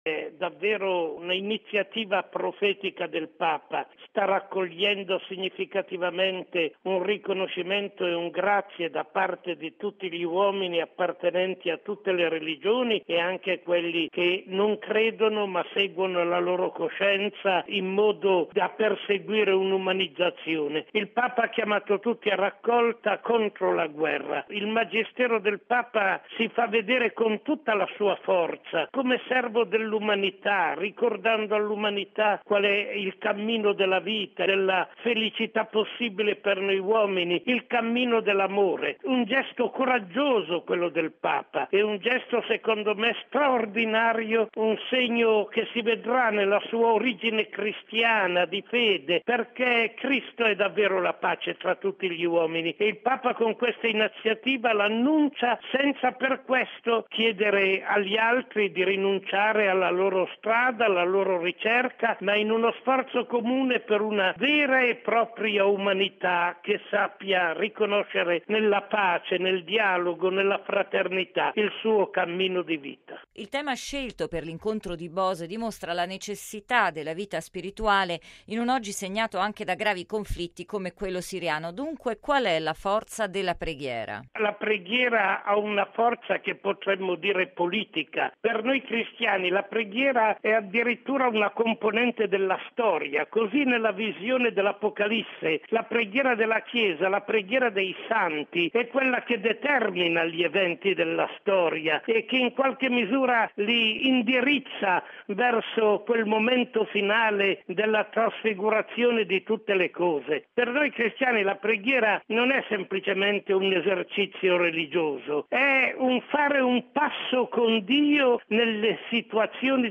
ha intervistato il priore di Bose, Enzo Bianchi: